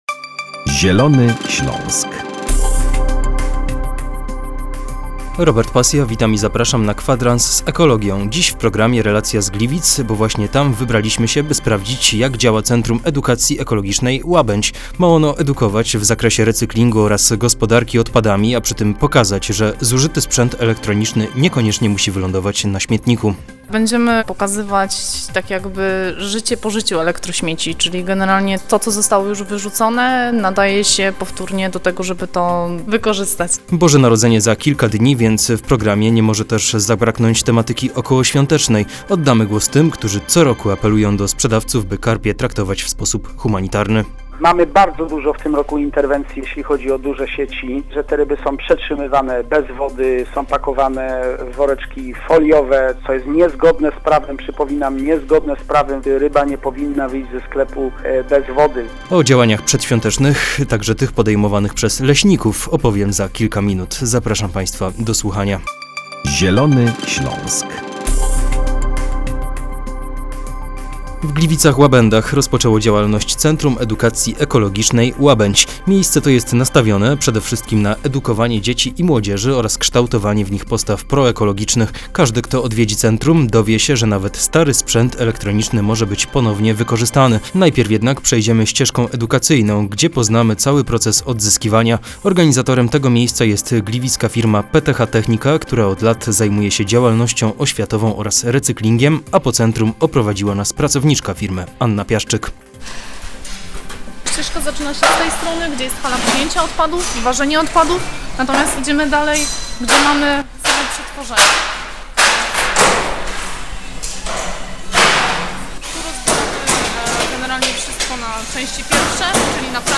W programie relacja z Gliwic, bo właśnie tam wybraliśmy się, by sprawdzić, jak działa Centrum Edukacji Ekologicznej "Łabędź". Ma ono edukować w zakresie recyklingu oraz gospodarki odpadami, a przy tym pokazać, że zużyty sprzęt elektroniczny niekoniecznie musi wylądować na śmietniku. Jak co roku przed świętami, Klub Gaja przypomina o humanitarnym traktowaniu karpi.